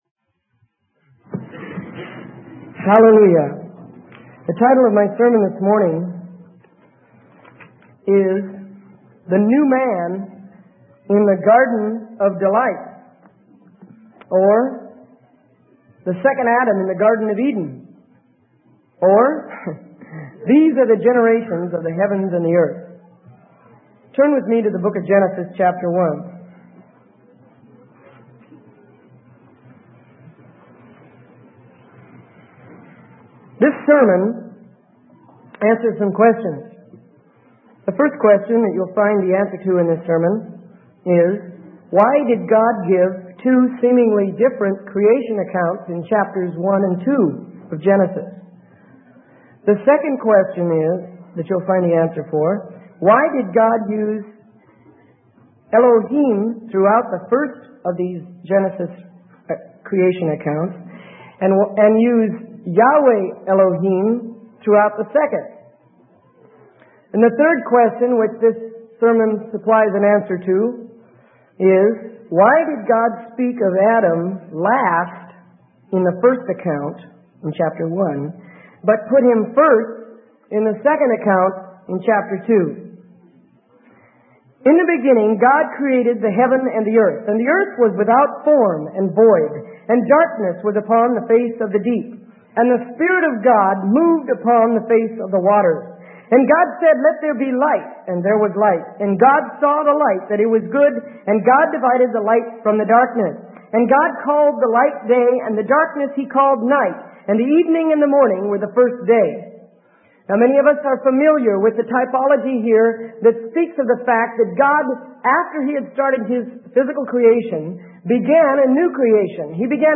Sermon: The New Man in the Garden of Delights - Freely Given Online Library